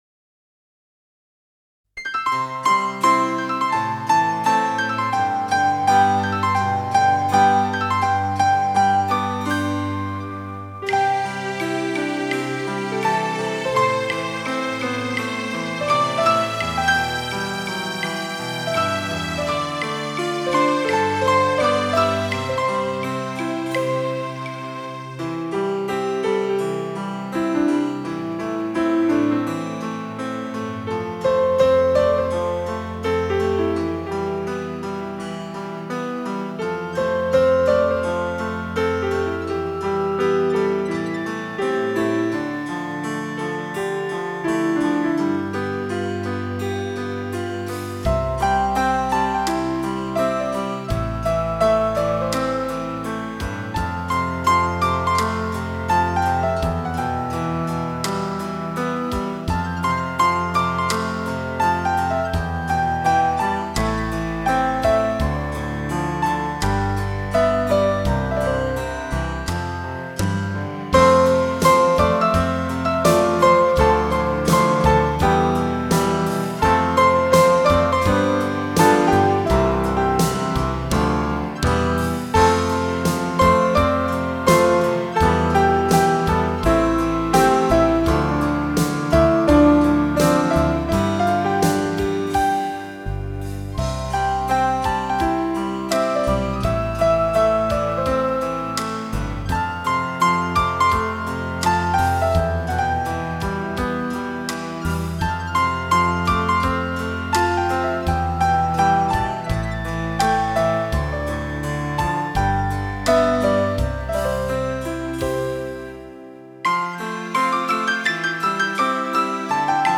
纯音